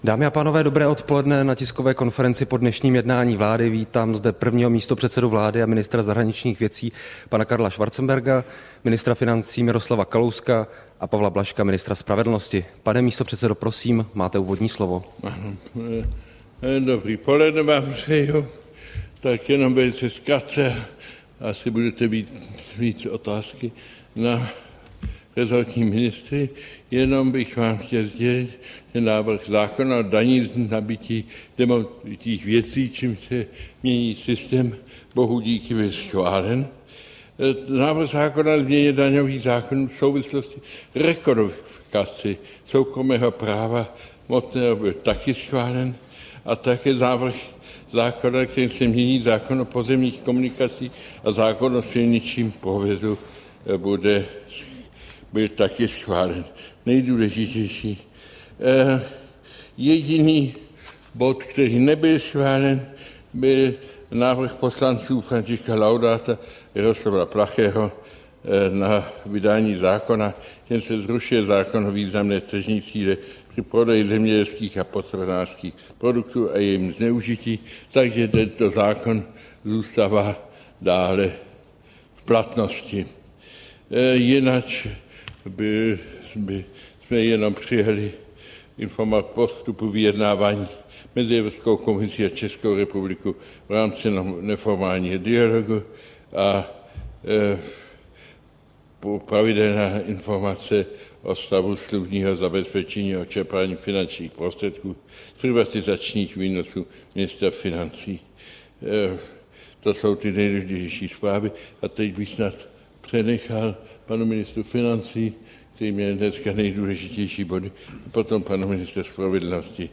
Tisková konference po jednání vlády, 17. dubna 2013